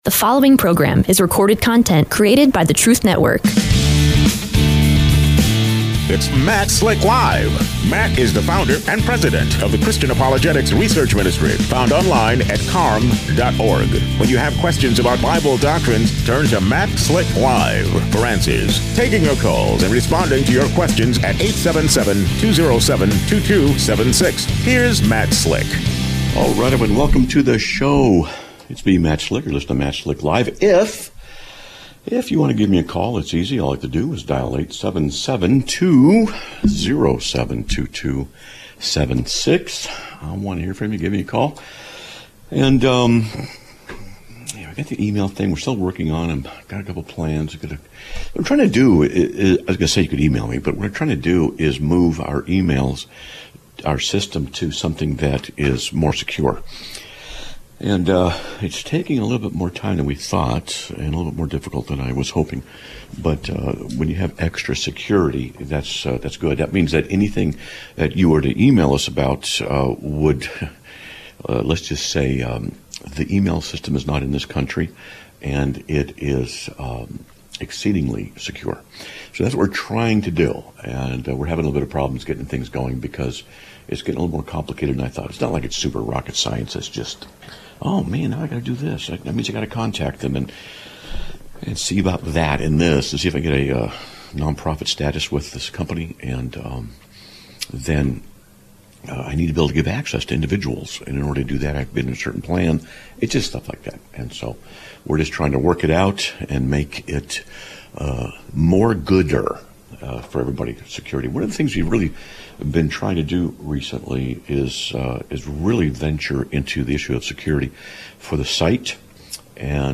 Live Broadcast of 01/08/2026